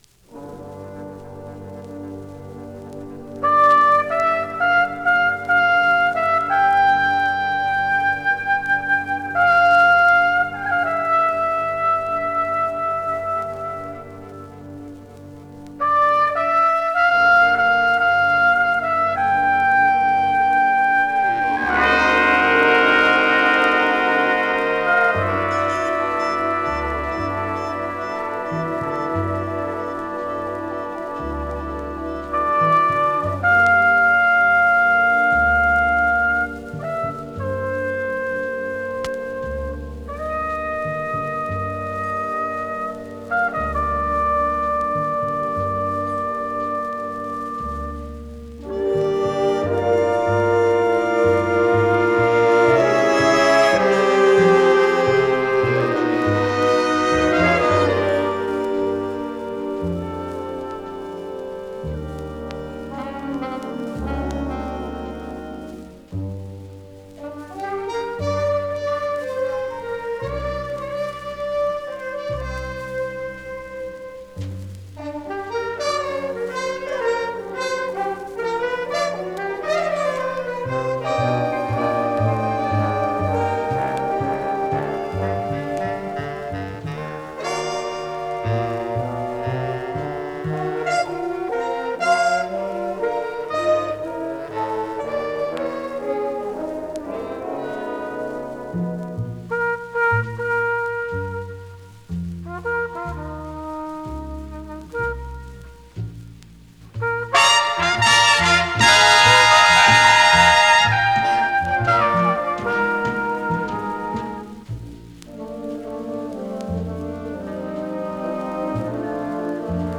全体的に軽いチリチリ・ノイズ。
MONO盤です。